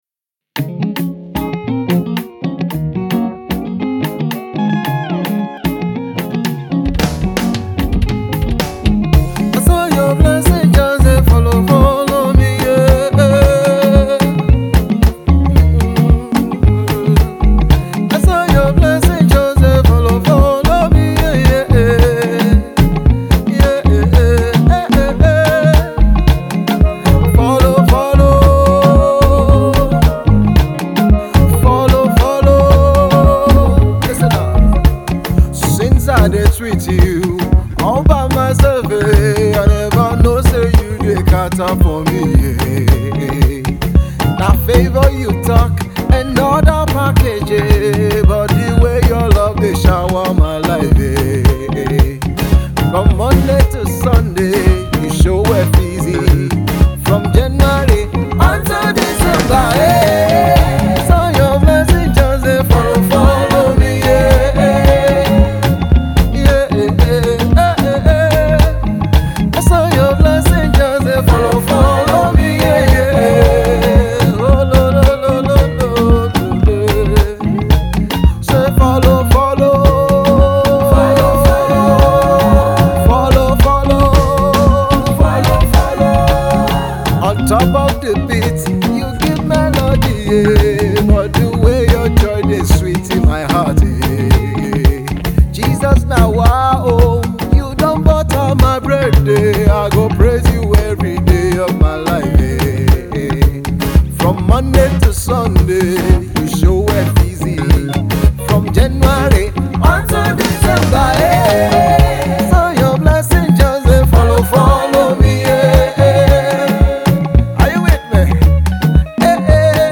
Nigerian gospel music minister